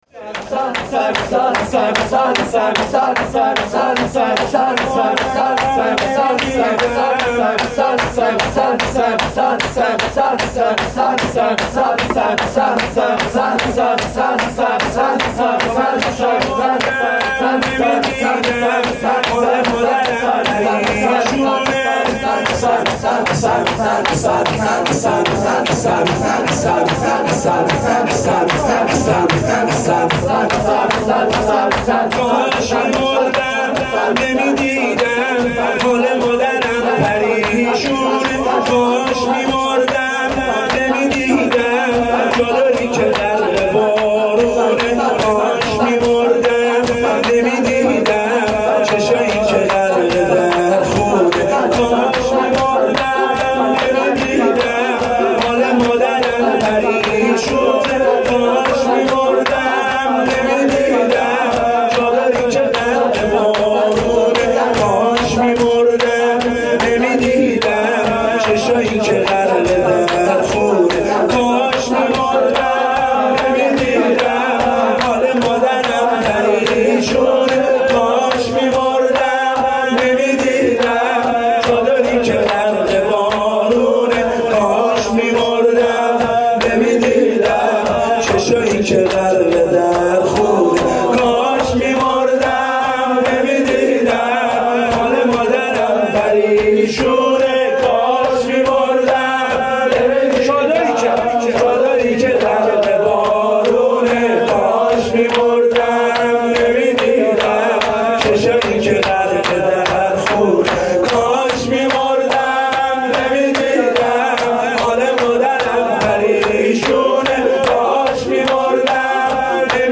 نوای فاطمیه, مداحی فاطمیه